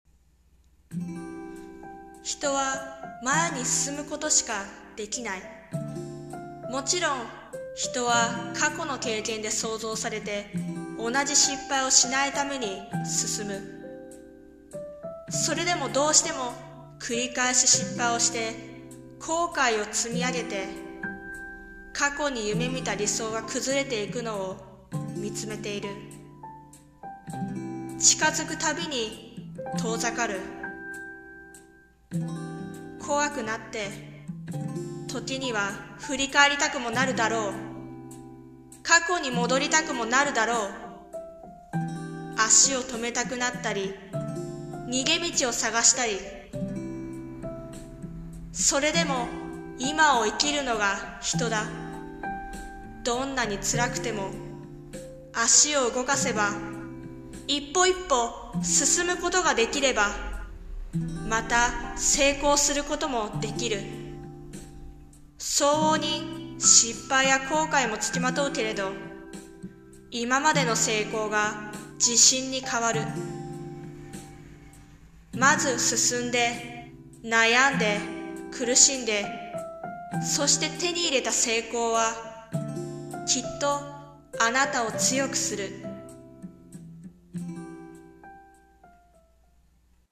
さんの投稿した曲一覧 を表示 進むということ【朗読】